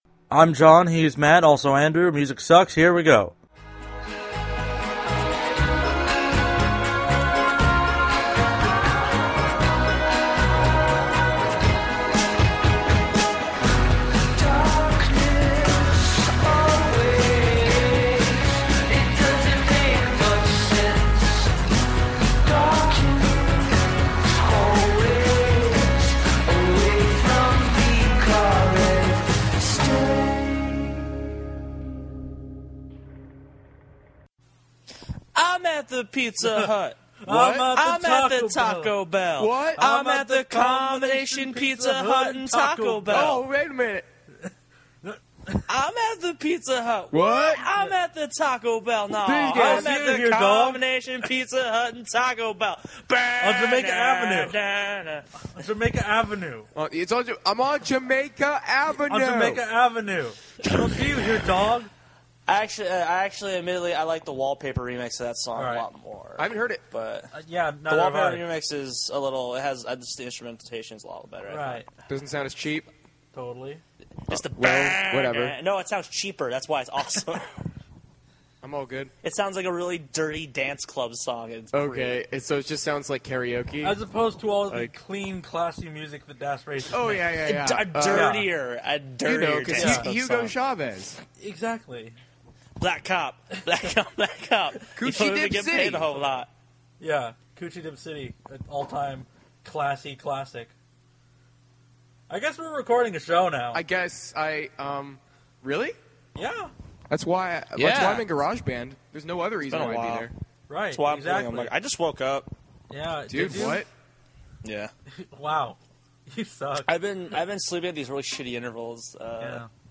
weird reverb